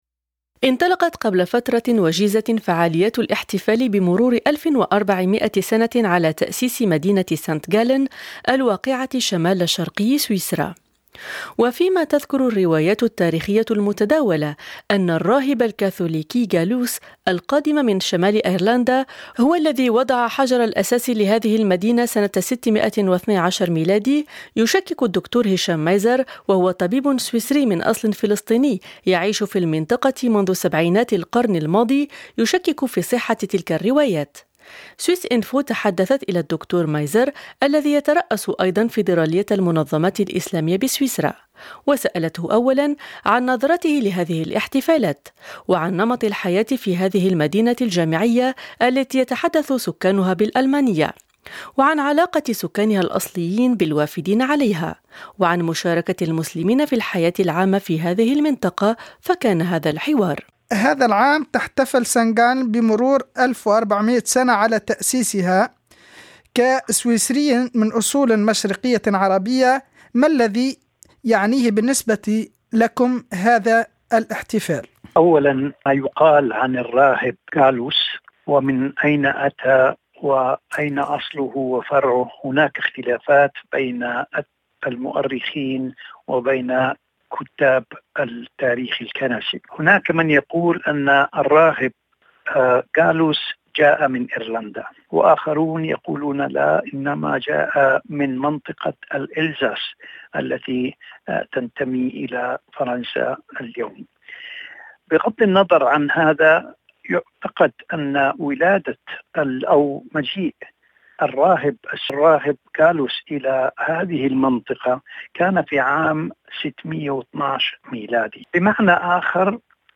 في حوار خاص